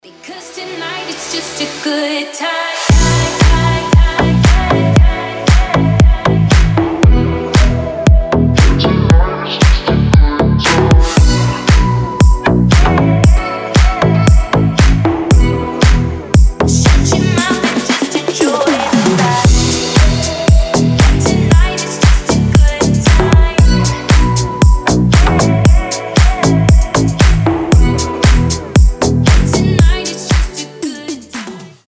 • Качество: 320, Stereo
позитивные
deep house
nu disco
теплые
ремиксы